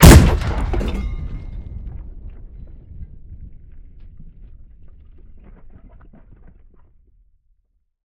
tank-cannon-2.ogg